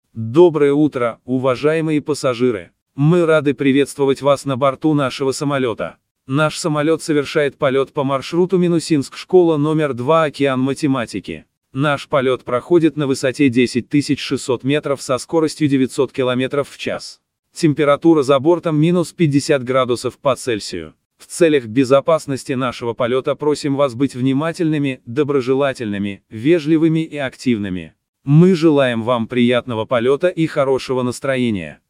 Запись голоса со слайда в самолёте –
Голос (записан ИИ)